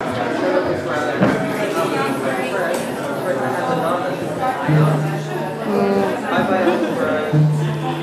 Room_noise1.mp3